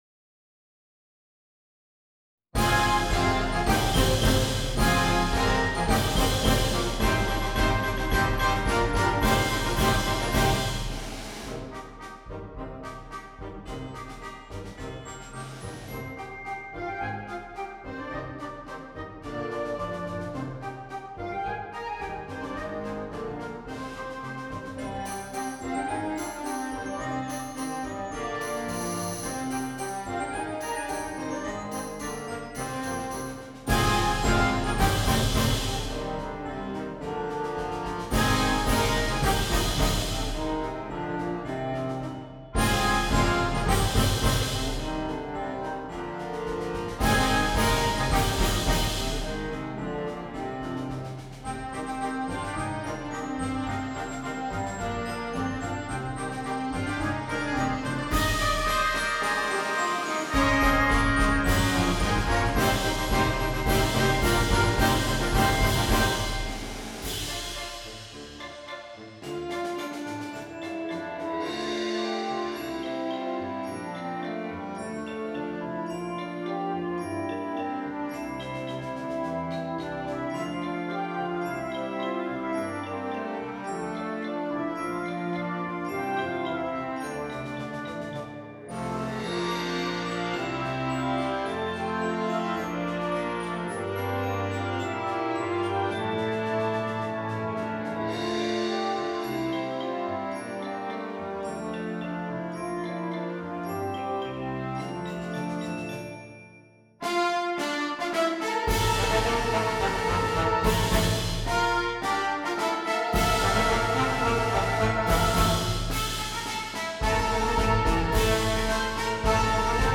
Voicing: Flex March